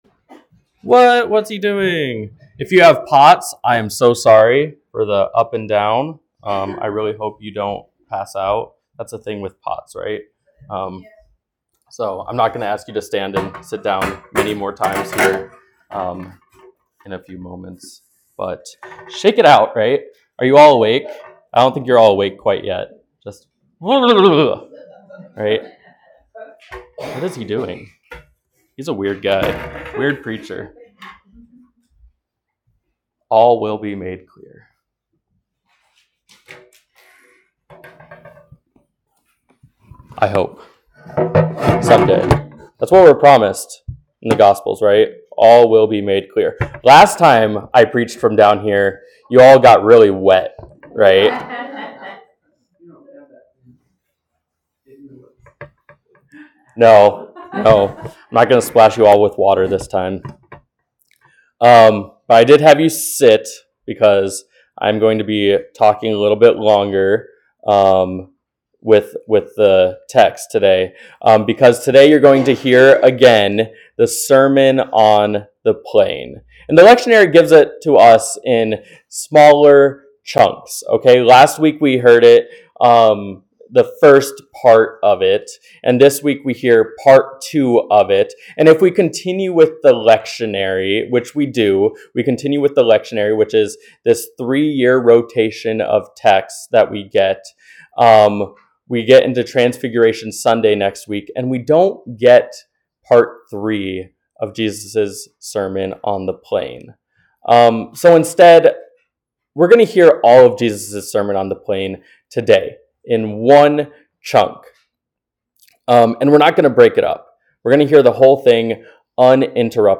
Sermons | Bethany Lutheran Church